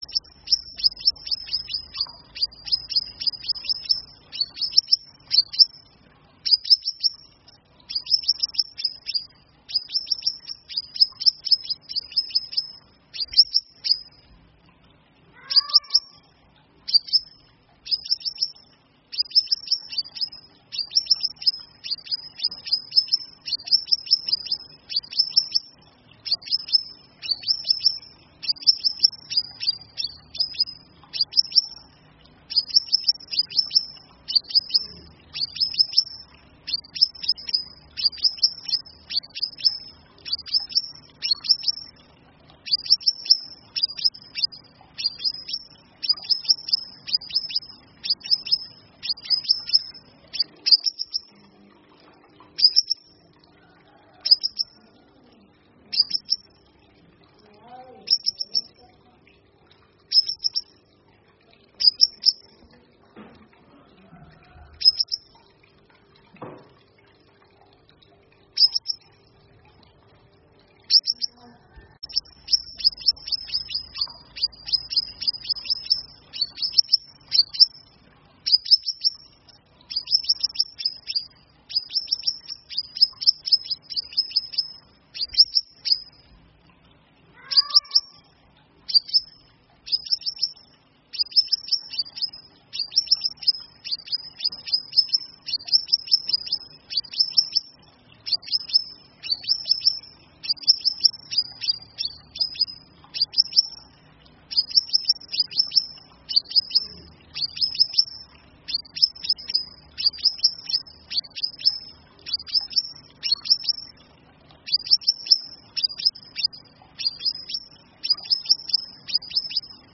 Tiếng chim Hút Mật Xác Pháo
Thể loại: Tiếng chim
Description: Tiếng chim hút mật xác pháo là âm thanh chân thực, chuẩn nhất trong thiên nhiên, thể hiện cuộc sống phong phú của thế giới động vật trong rừng núi.
tieng-chim-hut-mat-xac-phao-www_tiengdong_com.mp3